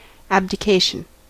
Ääntäminen
IPA: [ab.di.ka.sjɔ̃]